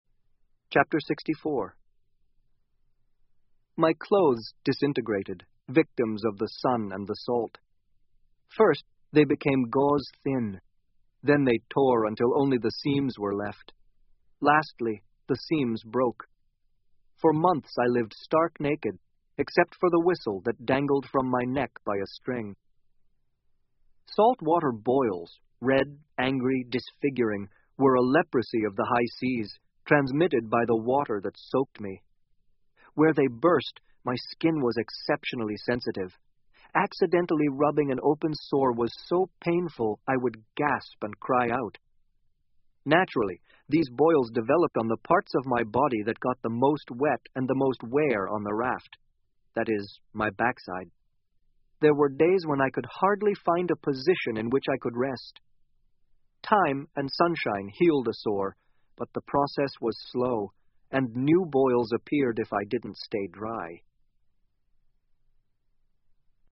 英文广播剧在线听 Life Of Pi 少年Pi的奇幻漂流 06-05 听力文件下载—在线英语听力室